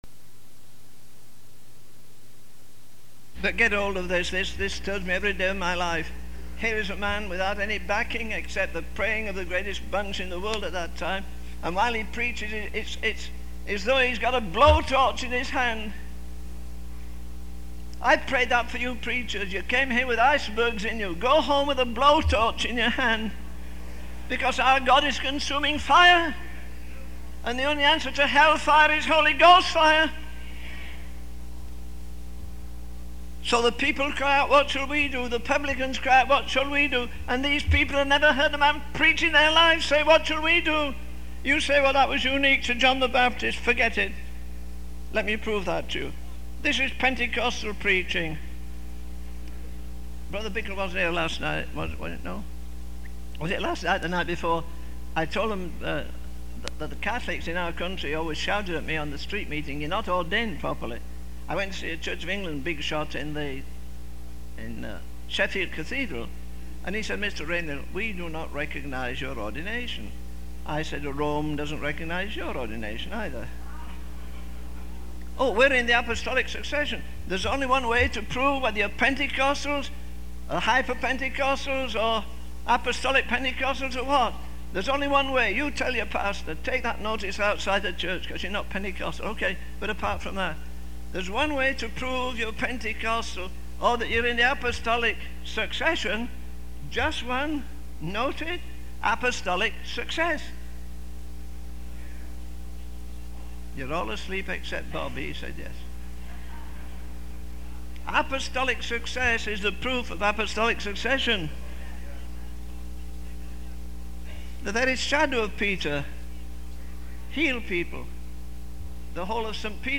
In this sermon, the preacher reflects on the experience of conviction and the response it elicits from individuals. The sermon emphasizes the need for personal introspection and a cry to God for guidance on what to do in the face of conviction. The preacher encourages the congregation to surrender their own plans and desires to align with the mind of God each day.